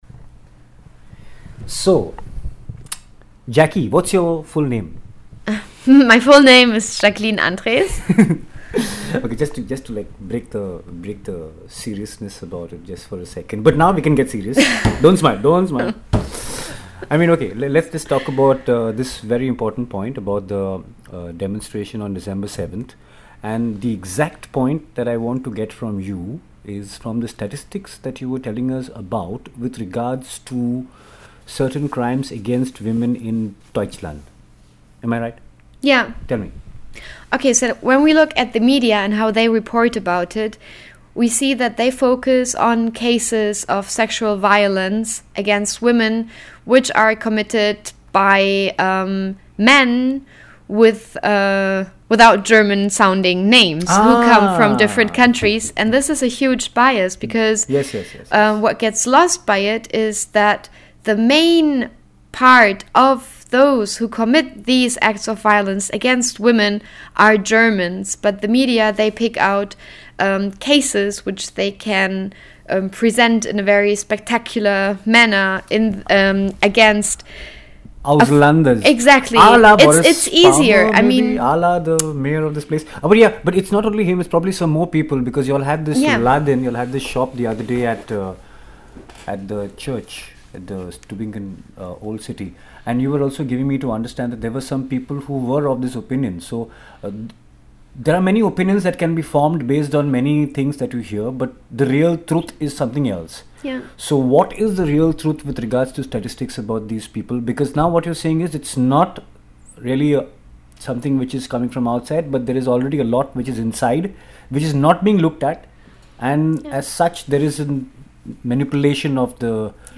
To know more, here is an interesting small chat with a research person from the IMI, who has researched statistics showing alarming situations in Deutschland itself, far from this misintreped version of 'all-evil-comes-from-outsiders' statements made by certain politicians clearly for ulterior motives.